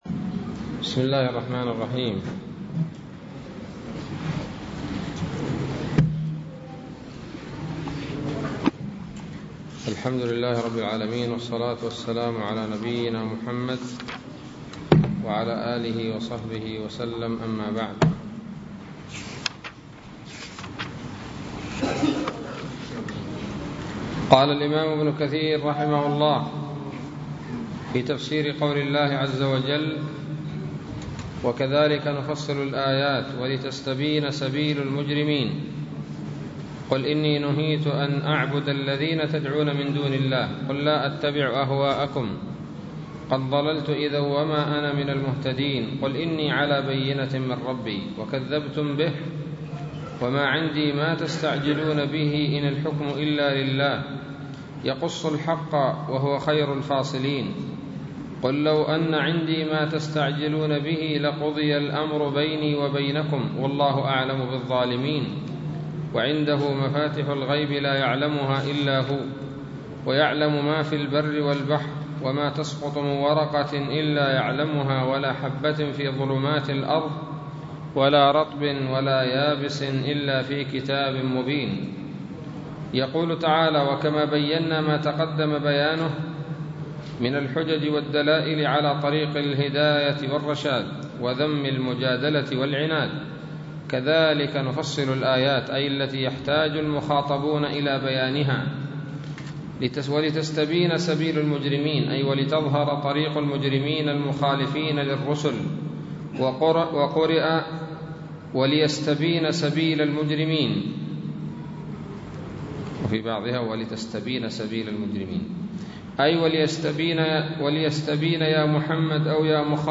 الدرس الثالث عشر من سورة الأنعام من تفسير ابن كثير رحمه الله تعالى